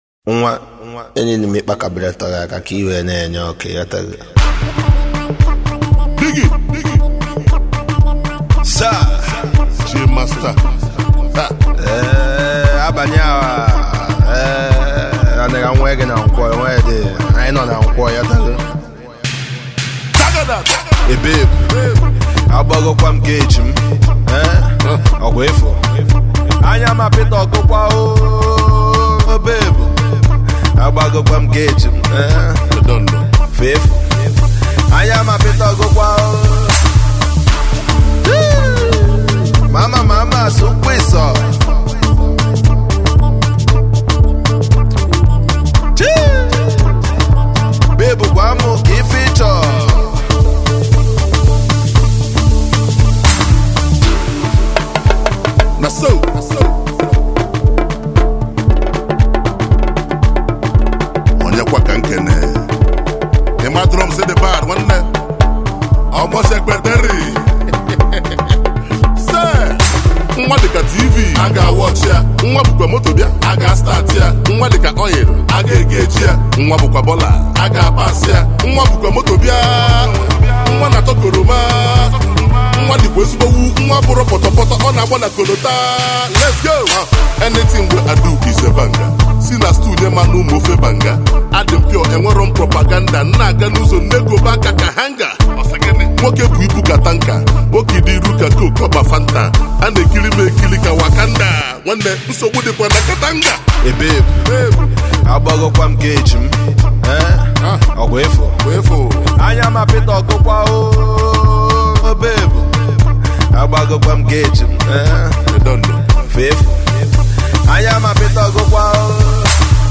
Fun filled single
its the Official the Street anthem for this Christmas.